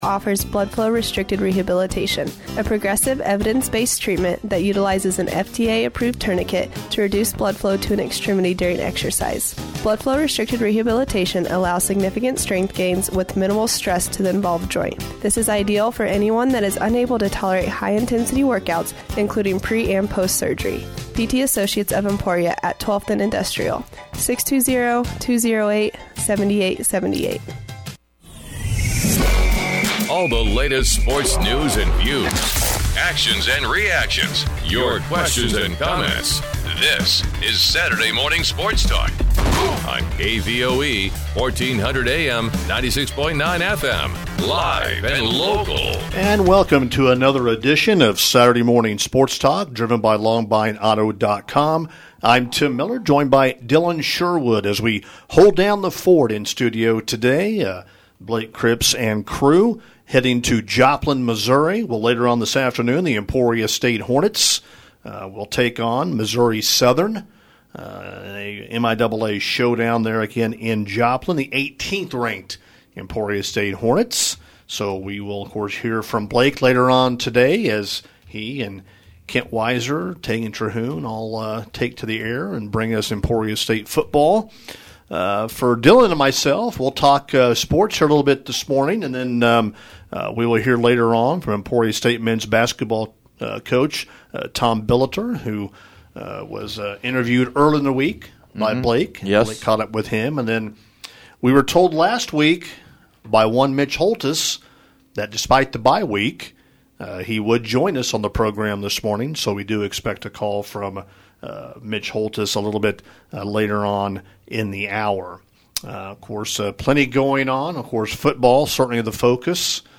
Saturday Morning Sports Talk